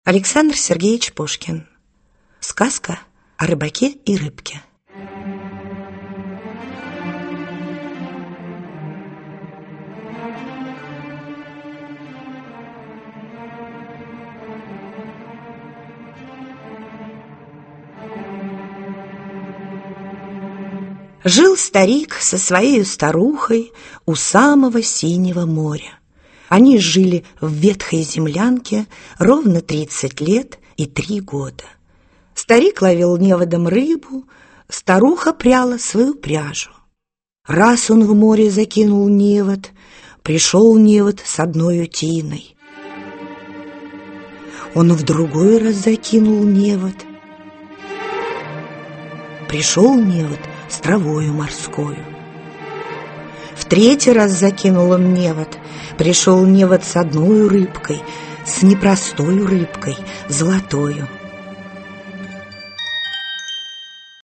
Аудиокнига Спящая царевна. Сказки и стихи русских поэтов | Библиотека аудиокниг